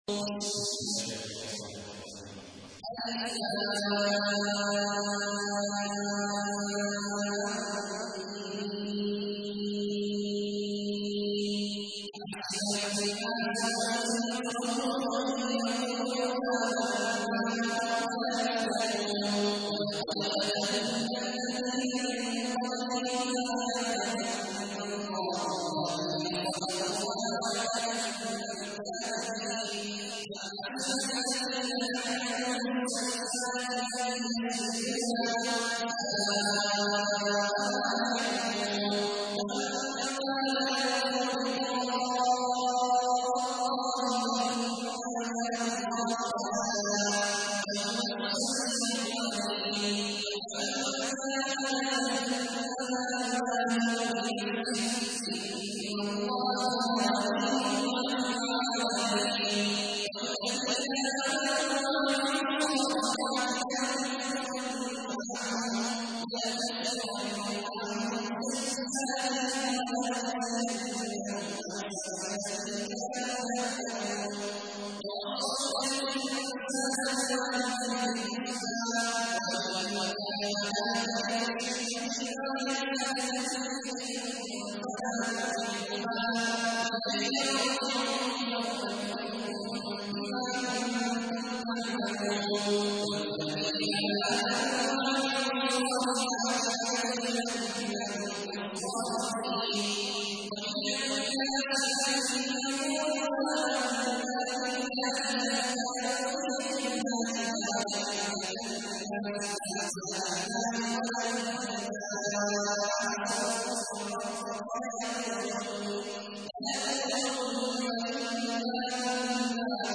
تحميل : 29. سورة العنكبوت / القارئ عبد الله عواد الجهني / القرآن الكريم / موقع يا حسين